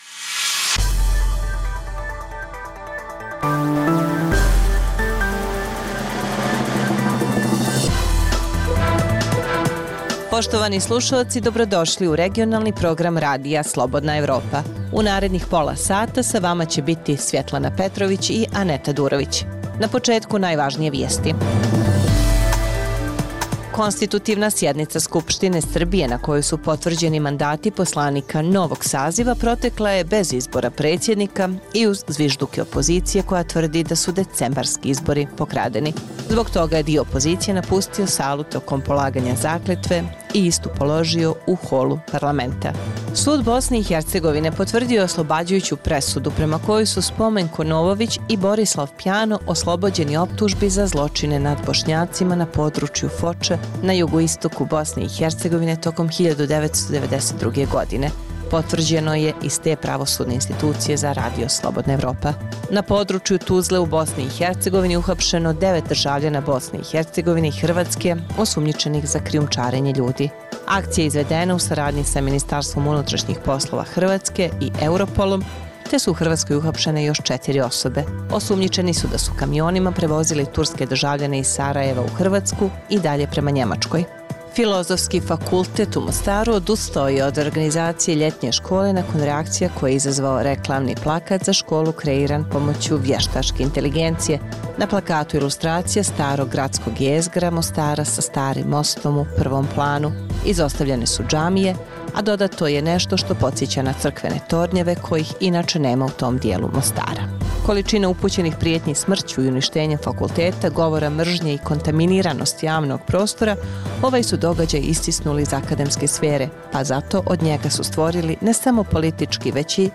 Preostalih pola sata emisije sadrži analitičke priloge iz svih zemalja regiona i iz svih oblasti, od politike i ekonomije, do kulture i sporta. Reportaže iz svakodnevnog života ljudi su svakodnevno takođe sastavni dio “Dokumenata dana”.